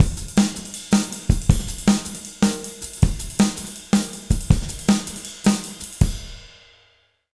160JUNGLE4-R.wav